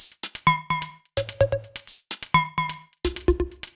Index of /musicradar/french-house-chillout-samples/128bpm/Beats
FHC_BeatD_128-01_Tops.wav